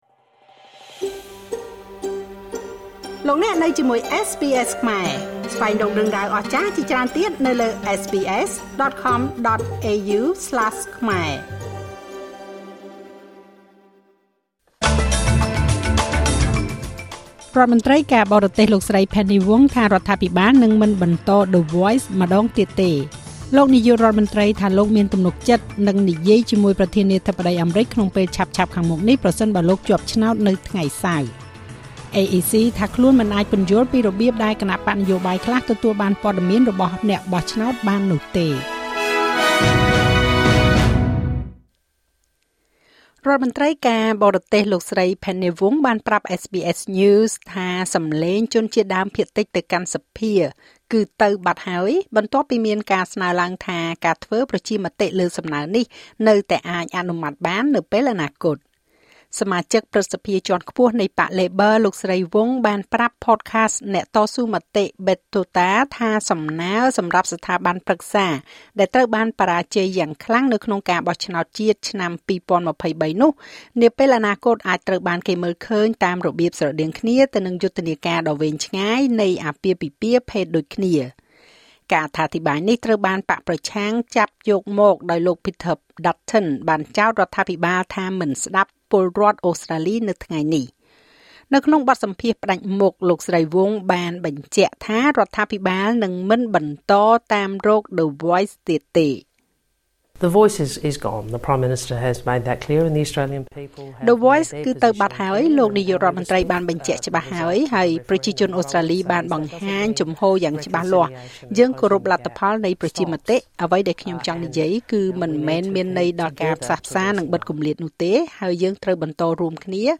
នាទីព័ត៌មានរបស់SBSខ្មែរ សម្រាប់ថ្ងៃពុធ ទី៣០ ខែមេសា ឆ្នាំ២០២៥